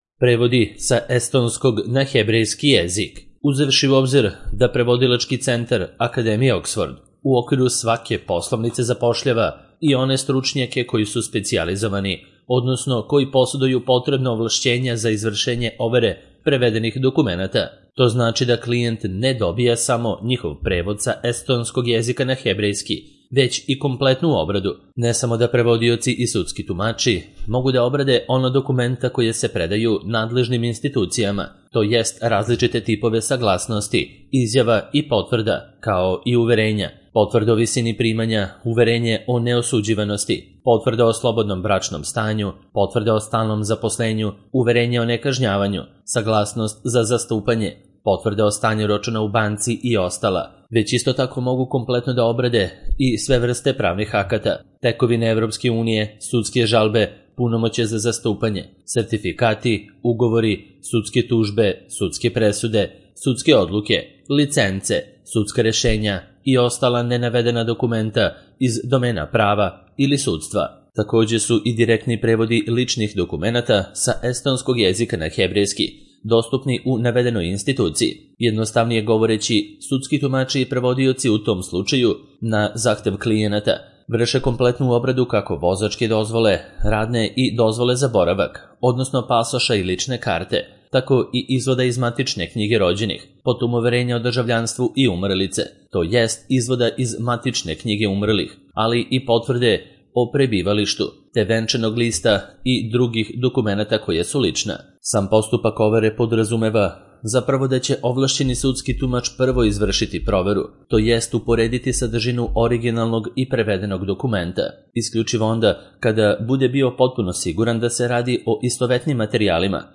Prevodi sa estonskog na hebrejski jezik - Audio verzija